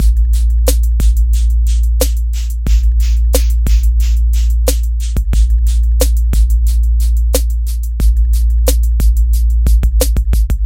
描述：鼓的循环（小鼓高帽振动器和踢腿） 享受
Tag: 90 bpm Hip Hop Loops Drum Loops 1.79 MB wav Key : Unknown